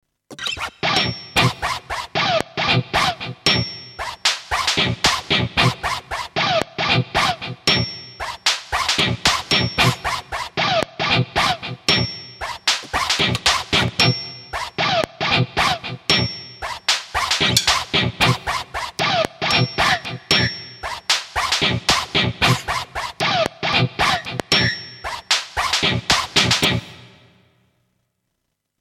なお、リンク先はSC-8850におけるそれぞれのドラムセットの音色を録音したものです。
*1 リンク先はSC-8850による該当音色でのサンプル演奏です。